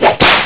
Sons de humor 47 sons
chicote1.wav